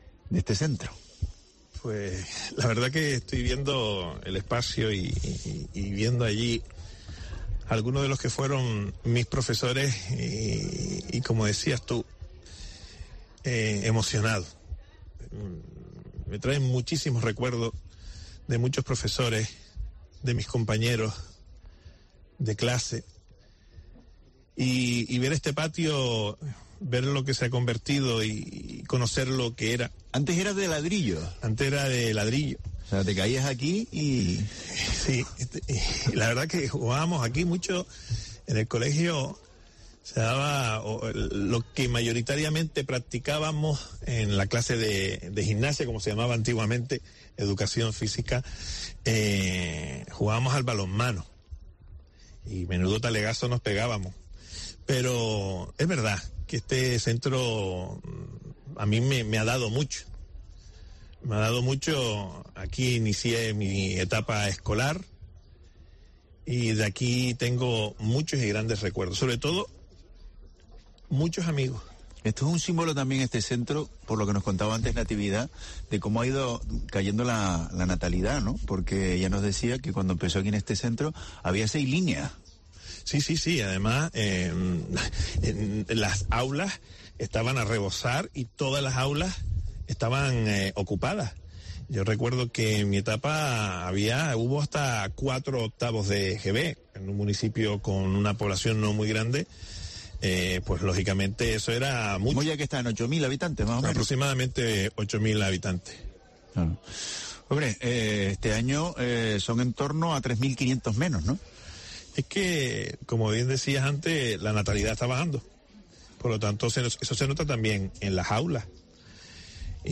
Poli Suárez, consejero de Educación del Gobierno de Canarias